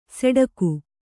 ♪ seḍaku